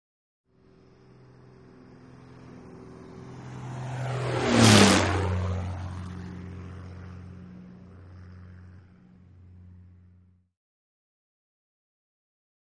Beechcraft: By Low; Beechcraft, 1978 E55 Baron. Hissing And Drone In Approach, High-pitched, Slicing Whoosh By, And Short Away. Medium To Distant Perspective. Prop Plane.